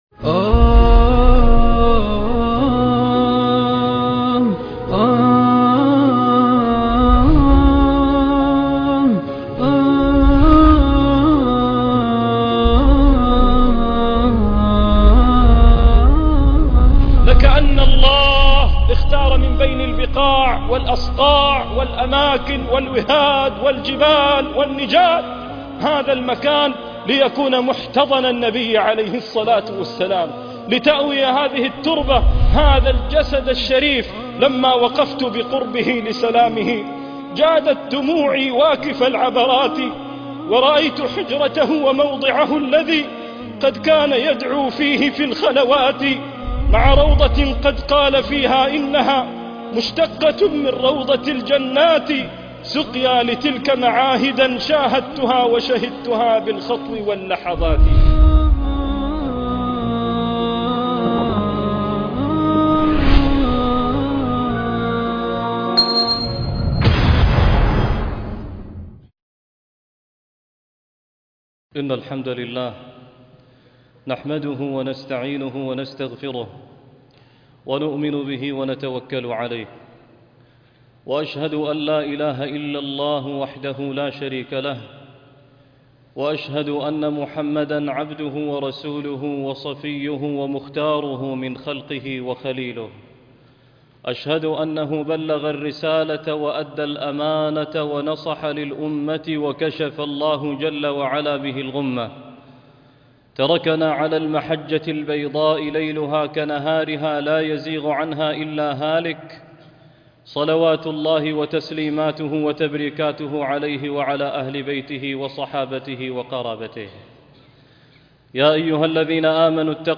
شهيد كربلاء - خطبة الجمعة